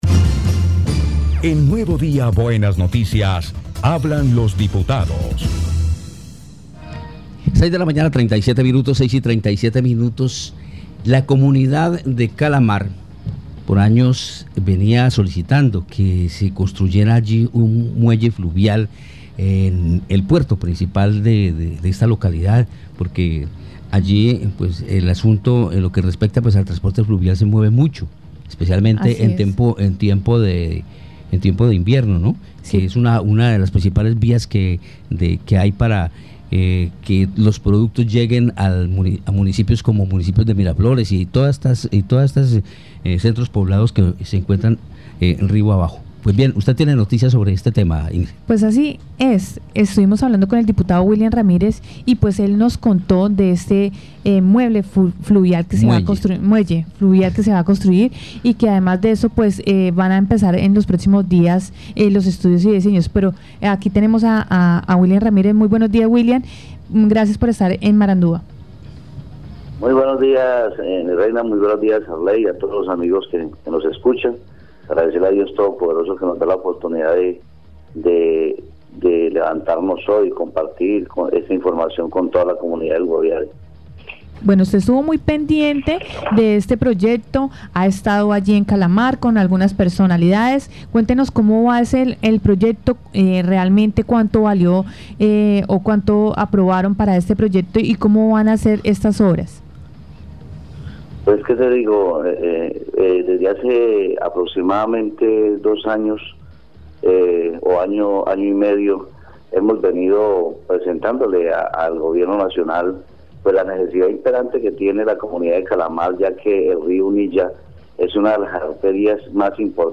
Escuche a William Ramirez, diputado del Guaviare.